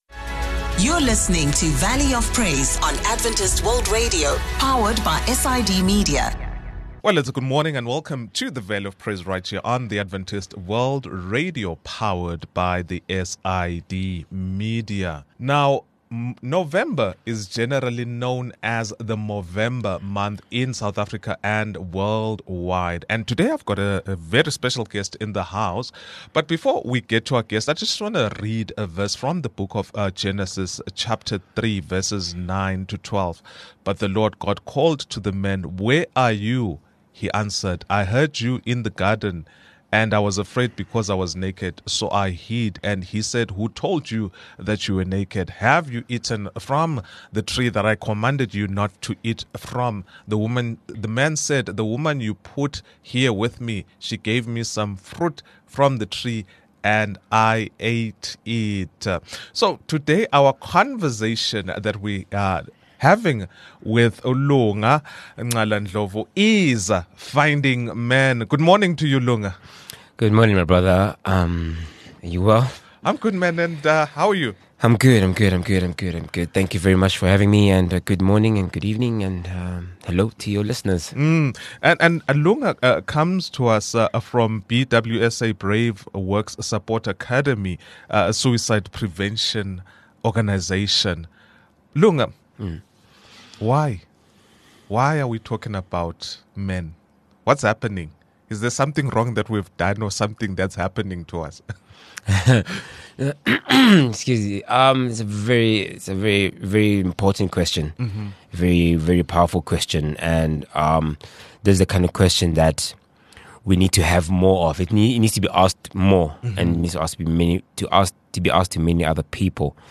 Discover how faith, vulnerability, and community can guide men to healing, purpose, and transformation. A powerful conversation on letting God lead the way!